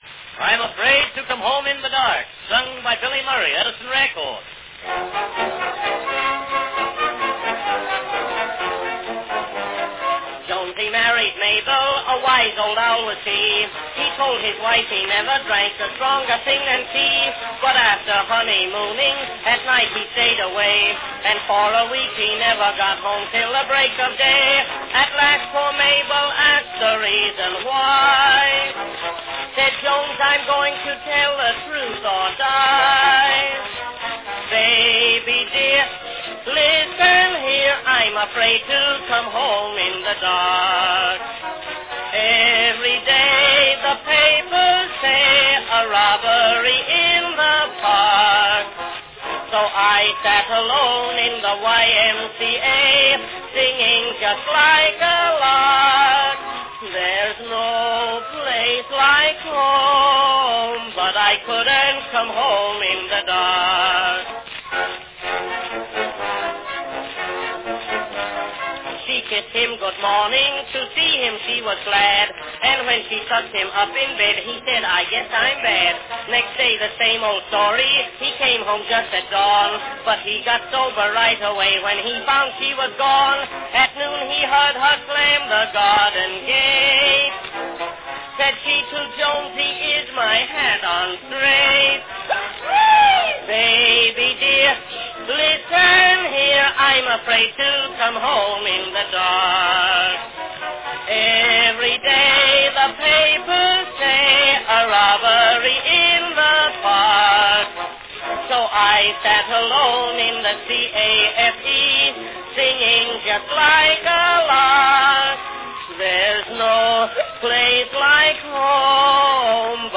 the comic song
Category Tenor solo